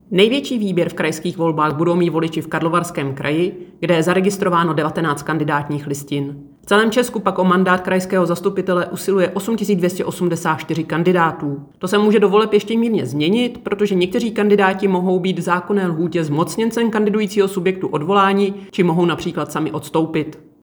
Vyjádření 1. místopředsedkyně Českého statistického úřadu Evy Krumpové ke krajským volbám, soubor ve formátu MP3, 808.17 kB